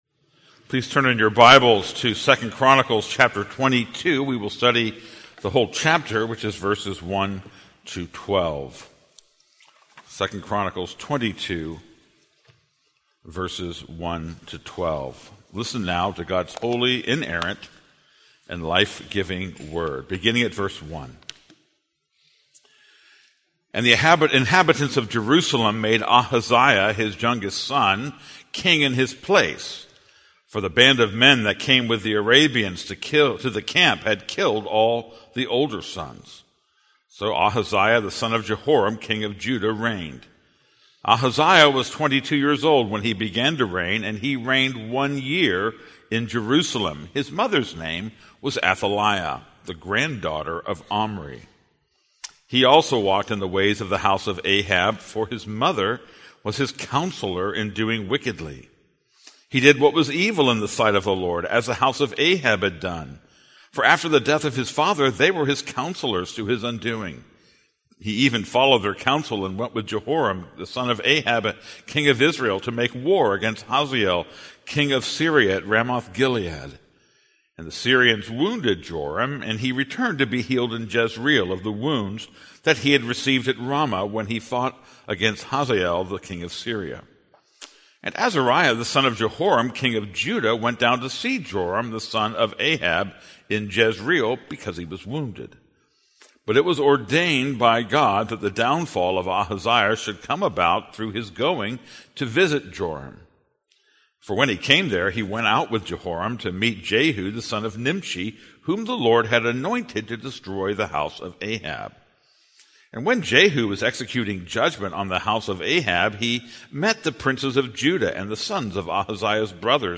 This is a sermon on 2 Chronicles 22:1-12.